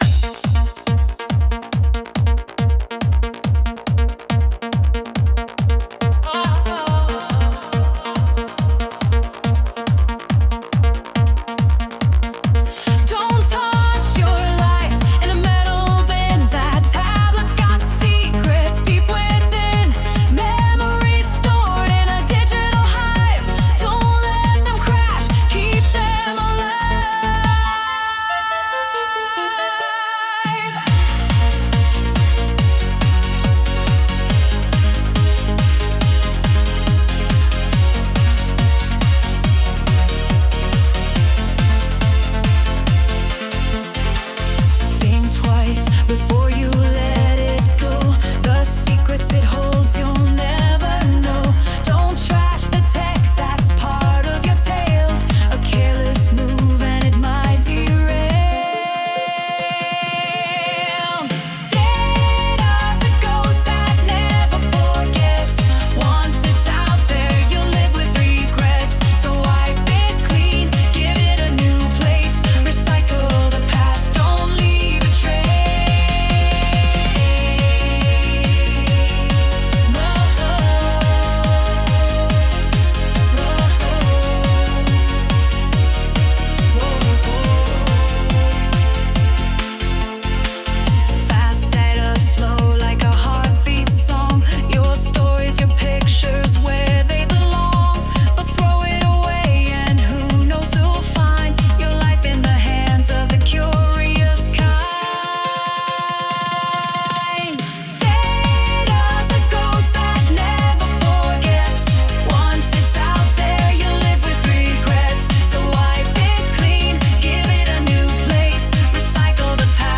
Powyższy utwór przerobiony przez AI na piosenkę.
Remix piosenki.